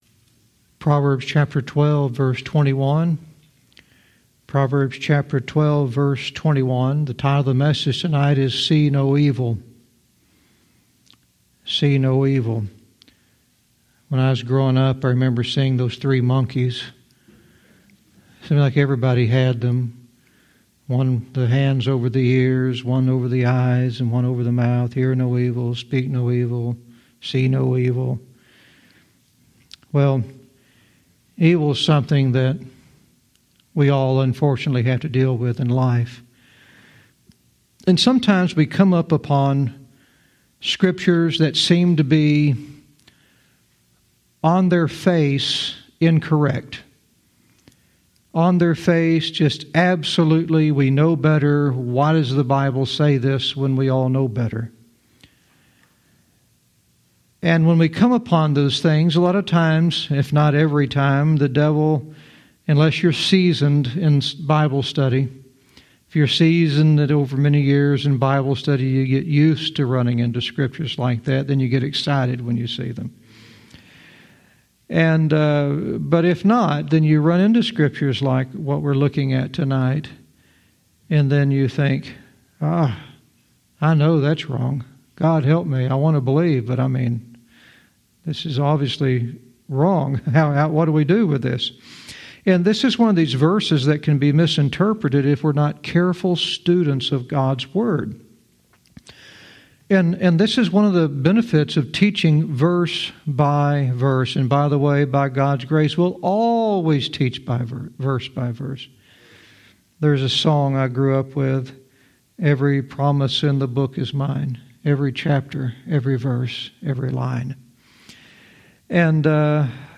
Verse by verse teaching - Proverbs 12:21 "See No Evil"